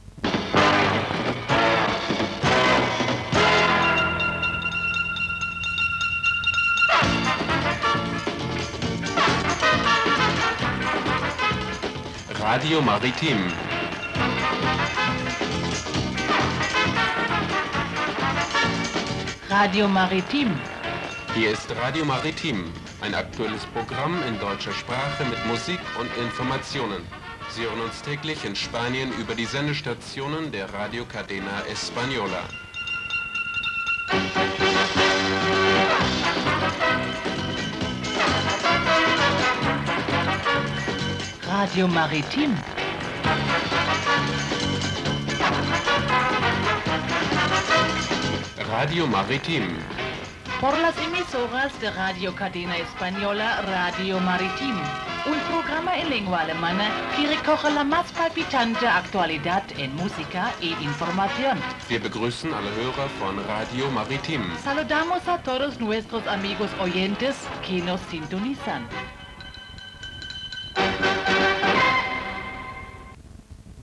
Careta del programa
Info-entreteniment